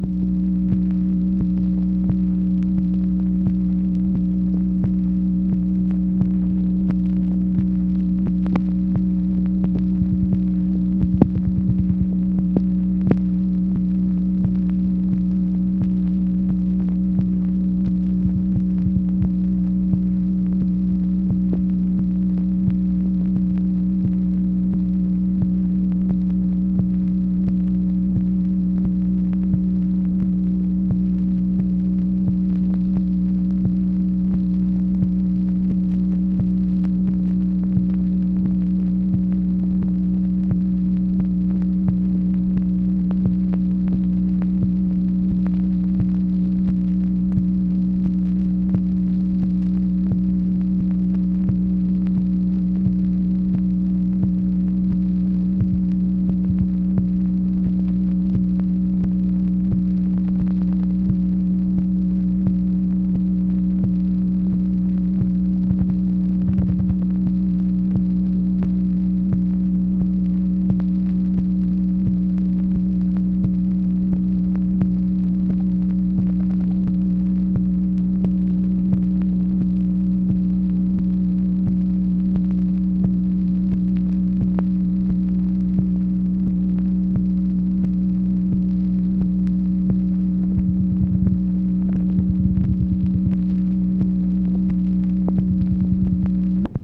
MACHINE NOISE, May 13, 1966
Secret White House Tapes | Lyndon B. Johnson Presidency